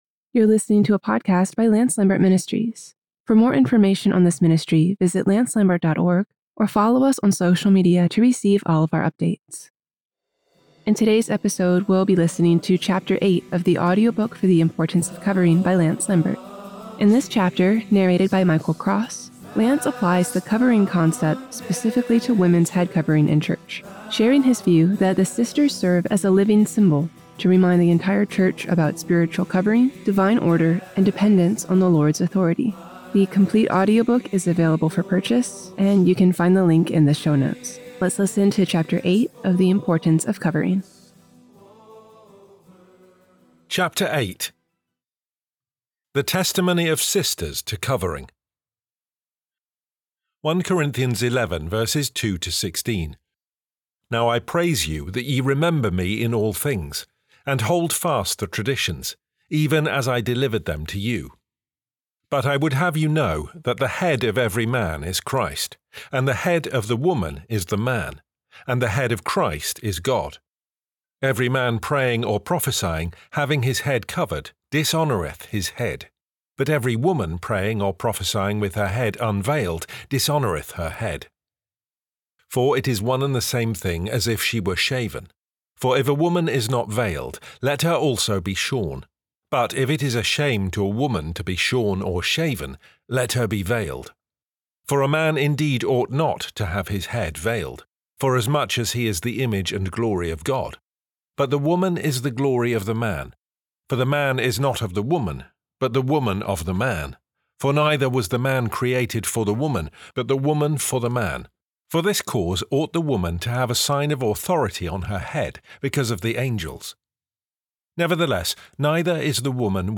The Importance of Covering — Audiobook Chapter 8